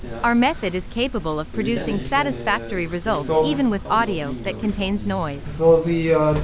noisy_audio_meeting_snr_0.wav